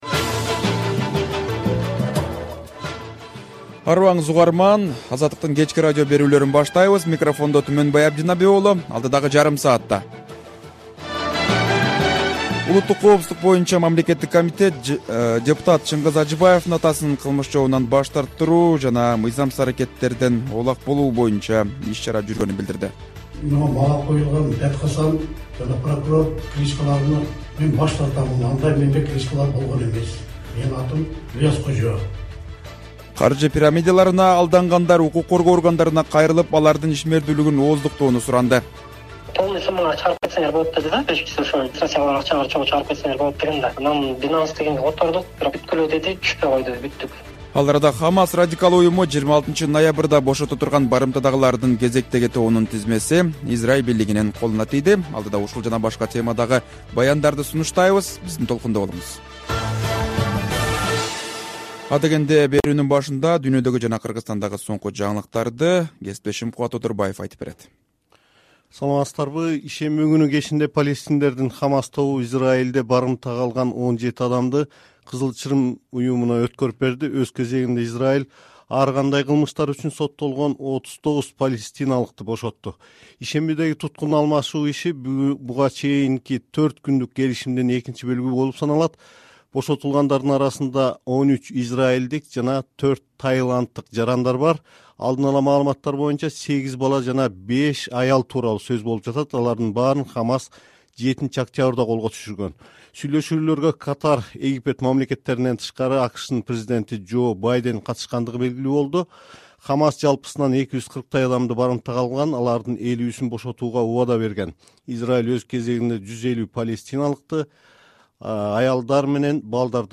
Кечки радио эфир | 26.11.2023 | Орусияда дрон чабуулунан улам аэропорттордун иши токтоп, кайра жанданды